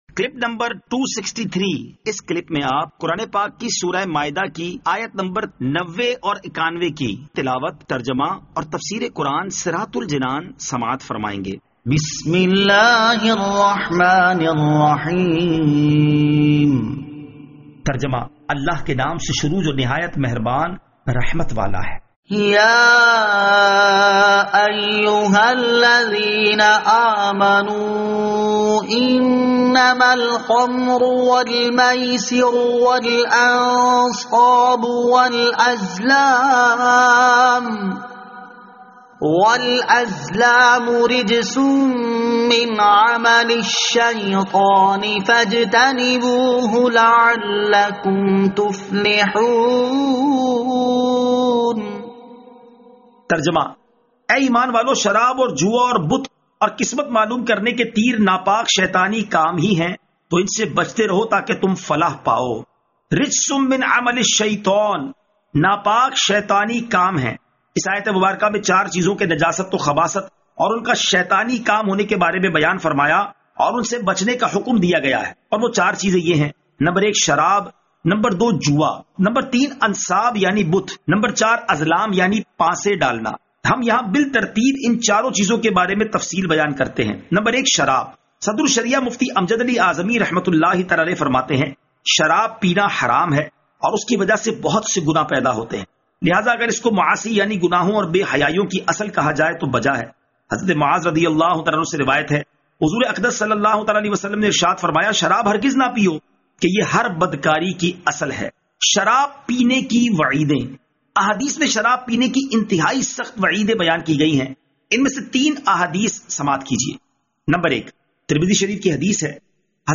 Surah Al-Maidah Ayat 90 To 91 Tilawat , Tarjama , Tafseer